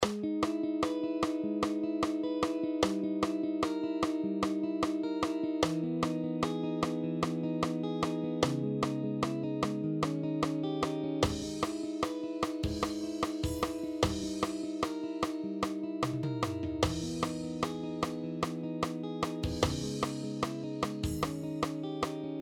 Example 3: 7/4 count